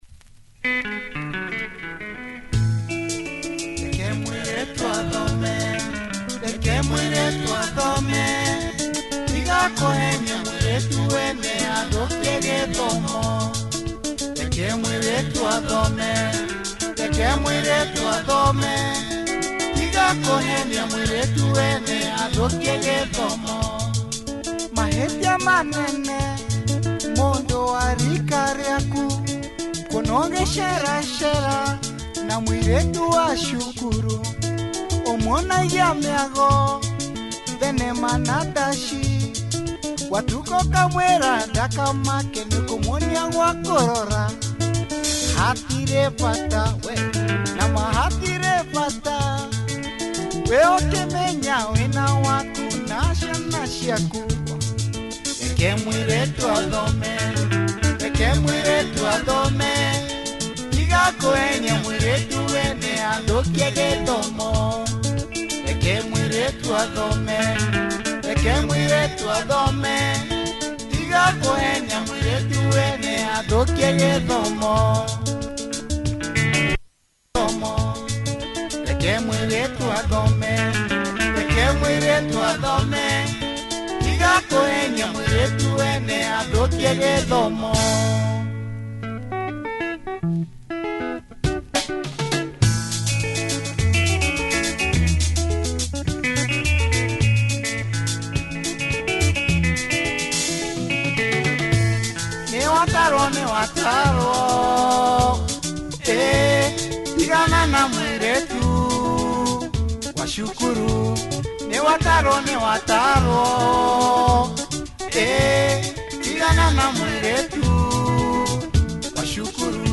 catchy, horns, nice drums, great guitar. https